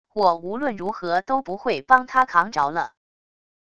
我无论如何都不会帮他扛着了wav音频生成系统WAV Audio Player